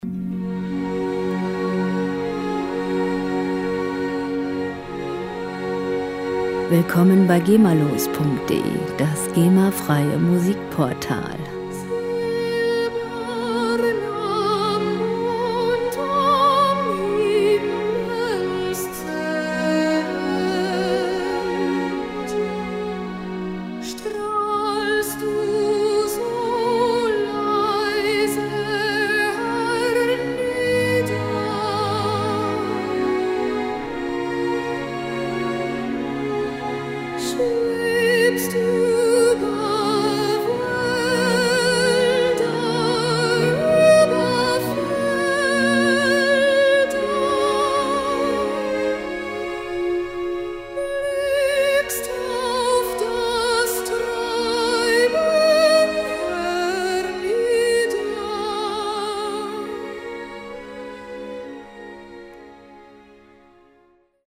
Klassische Musik - Tradition
Musikstil: Opern-Arie
Tempo: 63 bpm
Tonart: Fis-Dur
Charakter: märchenhaft, weich
Instrumentierung: Sopranistin, Sinfonieorchester, Harfe